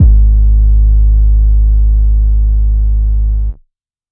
808s / 808 9.wav